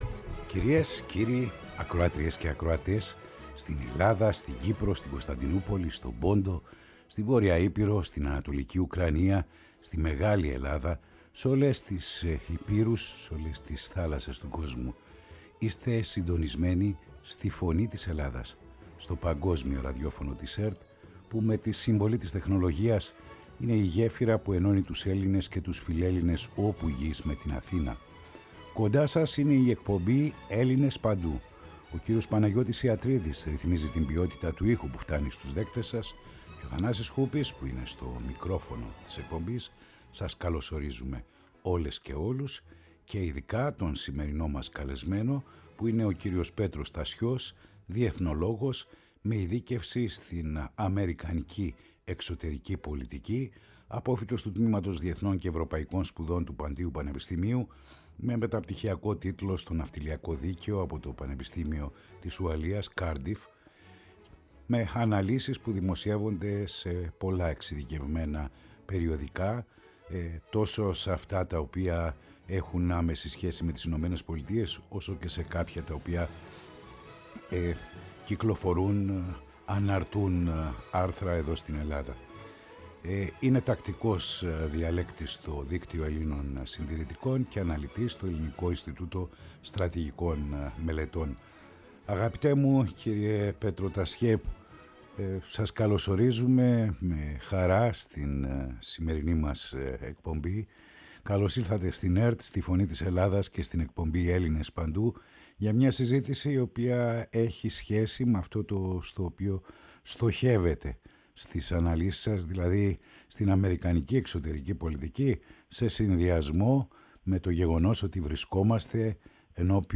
στους “Έλληνες παντού” Η ΦΩΝΗ ΤΗΣ ΕΛΛΑΔΑΣ Ελληνες Παντου ΣΥΝΕΝΤΕΥΞΕΙΣ Συνεντεύξεις